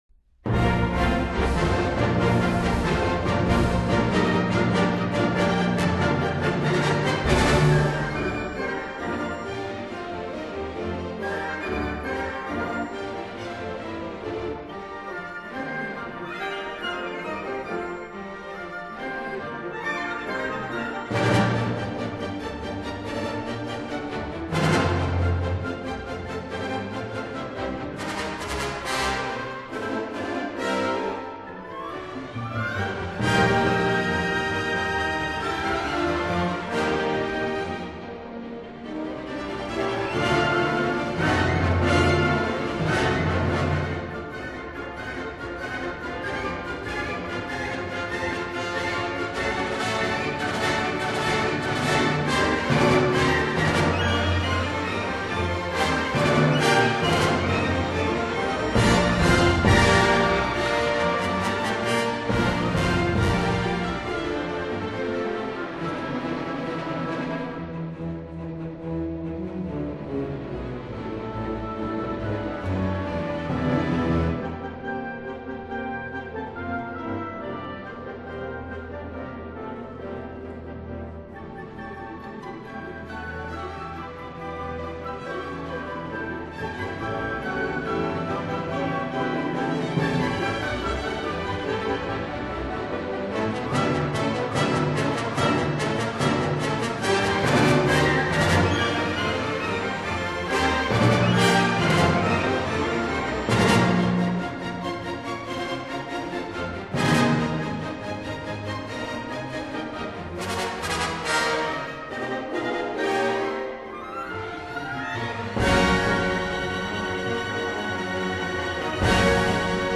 葛氏的音樂配器色彩豐富，旋律優美，寫作技巧精湛。
ballet